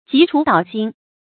急杵搗心 注音： ㄐㄧˊ ㄔㄨˇ ㄉㄠˇ ㄒㄧㄣ 讀音讀法： 意思解釋： 形容驚異不安的心情。